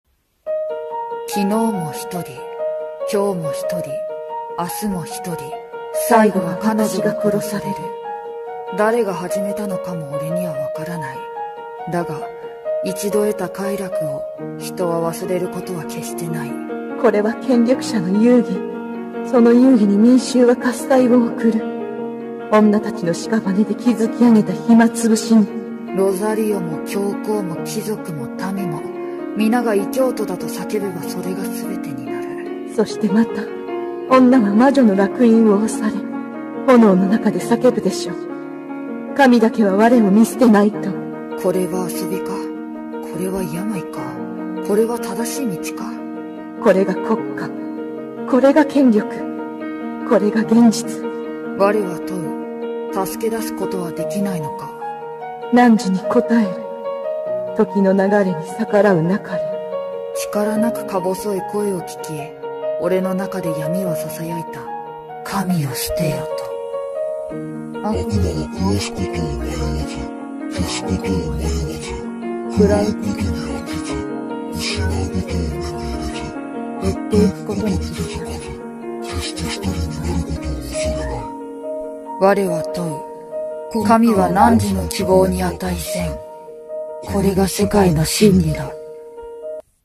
2人声劇「火刑の魔女達」